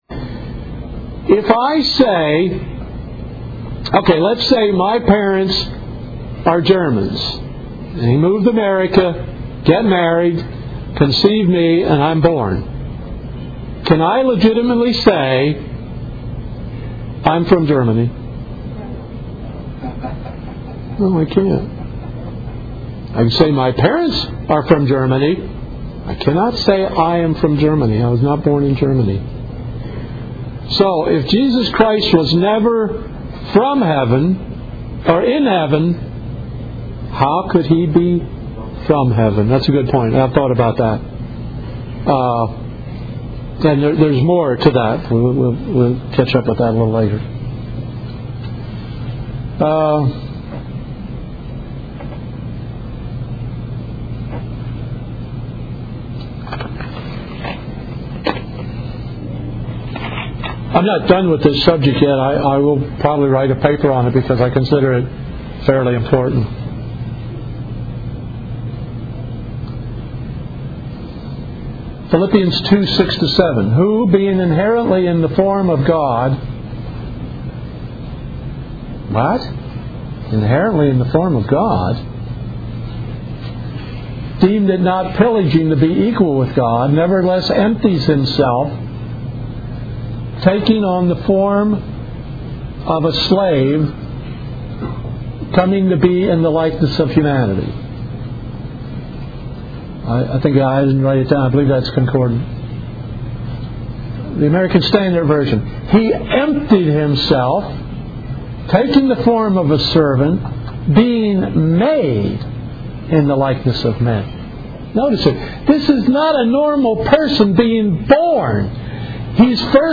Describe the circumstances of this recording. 2009 Mobile Conference